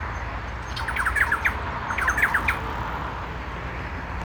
Ratona Grande (Campylorhynchus turdinus)
Nombre en inglés: Thrush-like Wren
Localidad o área protegida: Puerto Iguazú
Condición: Silvestre
Certeza: Fotografiada, Vocalización Grabada
Ratona-grande.mp3